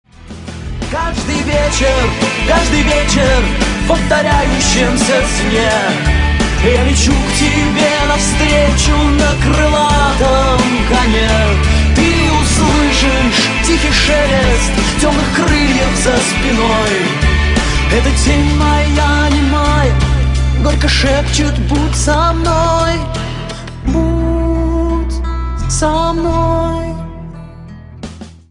• Качество: 128, Stereo
мужской вокал
грустные
OST
легкий рок